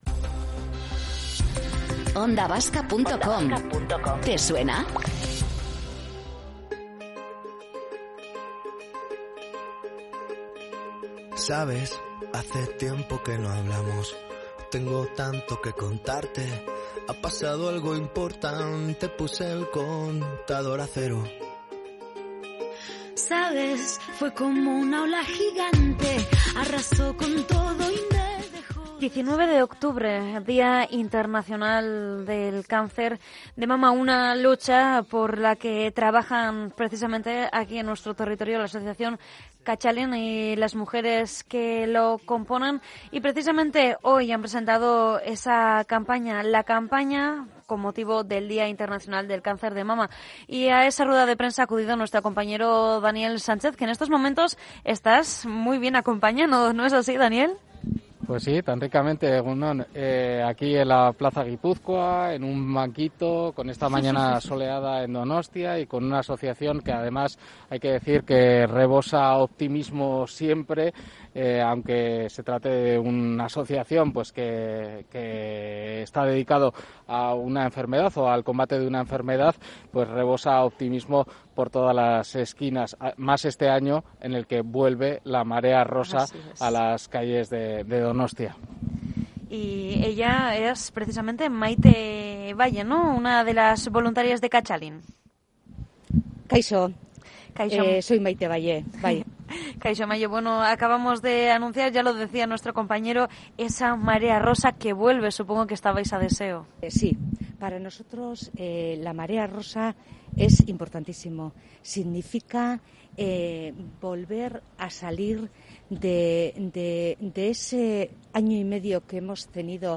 Magazine